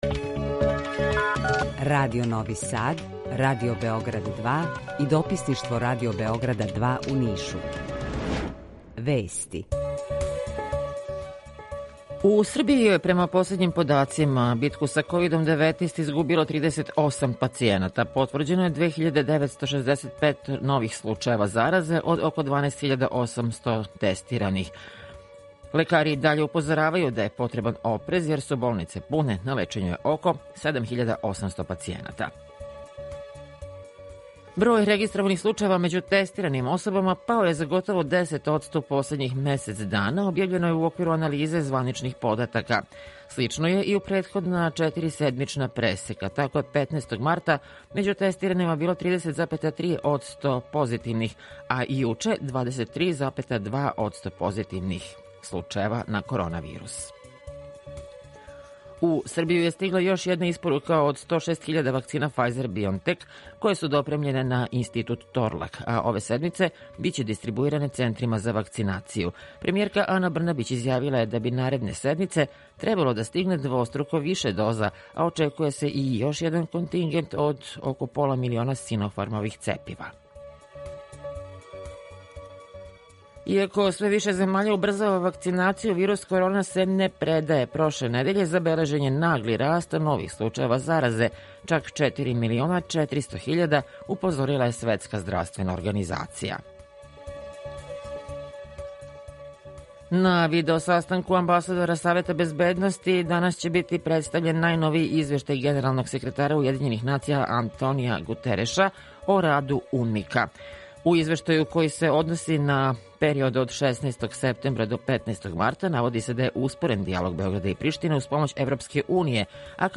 Јутарњи програм из три студија
Јутарњи програм заједнички реализују Радио Београд 2, Радио Нови Сад и дописништво Радио Београда из Ниша.